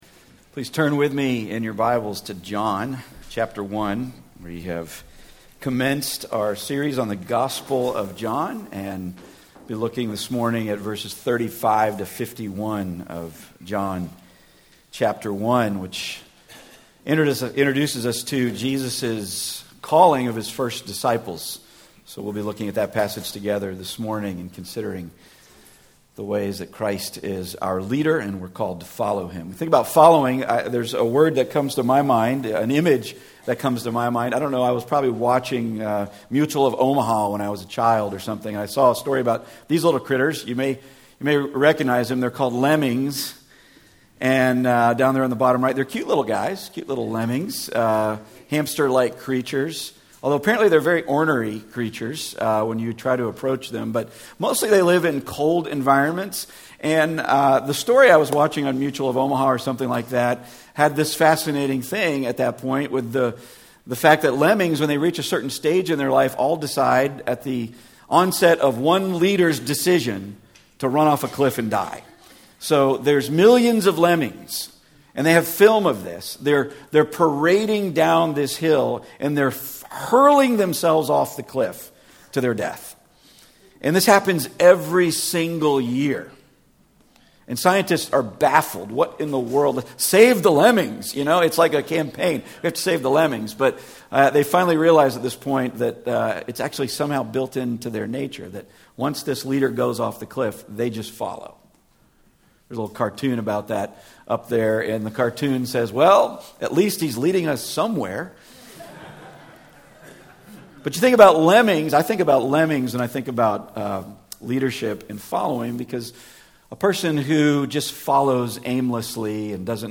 The Book of John Passage: John 1:35-51 Service Type: Weekly Sunday